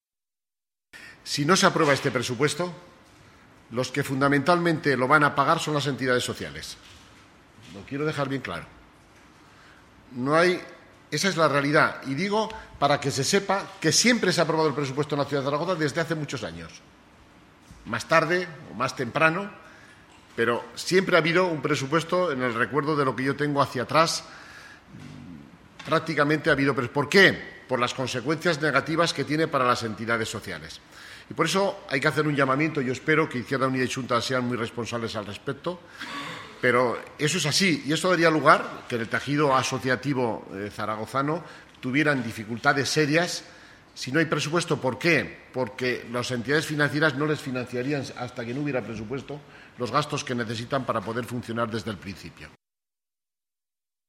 Sobre el gasto social y las consecuencias que se derivarían si no se aprueba el presupuesto, el vicealcalde y consejero de Economía y Hacienda, Fernando Gimeno, ha manifestado: